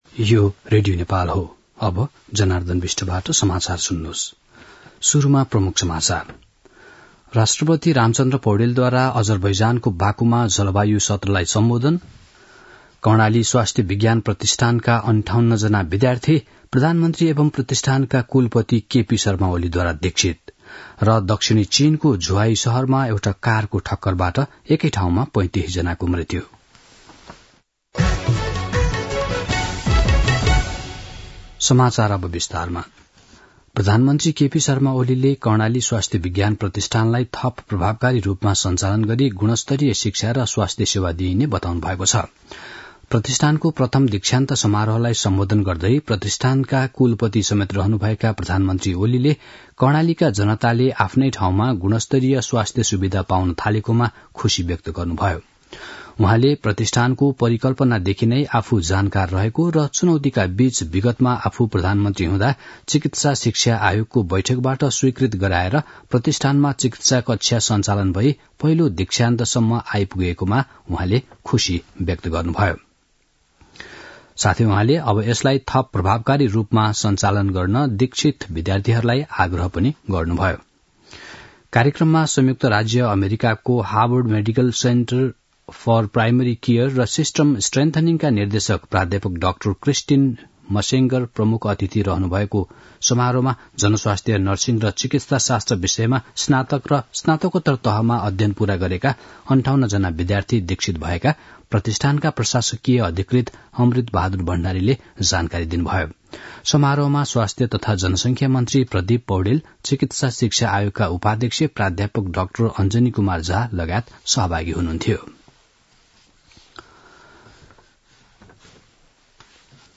दिउँसो ३ बजेको नेपाली समाचार : २९ कार्तिक , २०८१
3-pm-nepali-news-.mp3